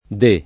vai all'elenco alfabetico delle voci ingrandisci il carattere 100% rimpicciolisci il carattere stampa invia tramite posta elettronica codividi su Facebook di [ di + ] (antiq. o region. de [ d %+ ]) s. m. o f. (lettera D) — cfr. vu